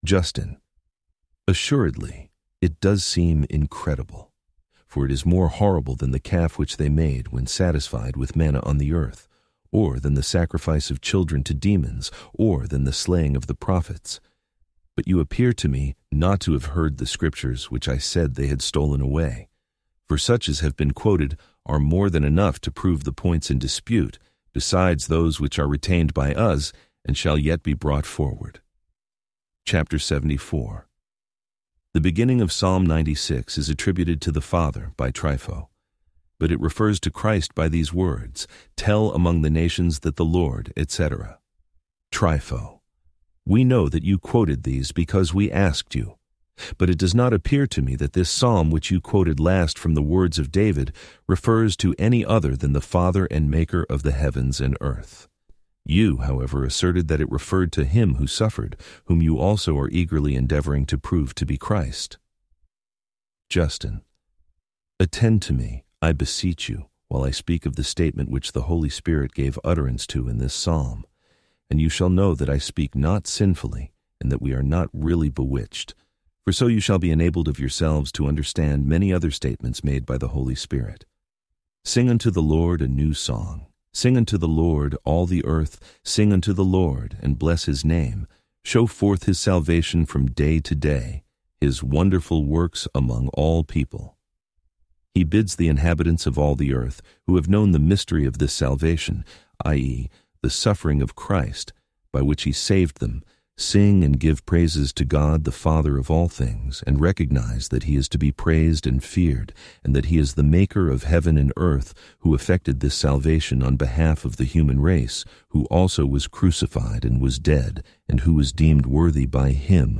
Complete Audiobook Play Download Individual Sections Section 1 Play Download Section 2 Play Download Listening Tips Download the MP3 files and play them using the default audio player on your phone or computer.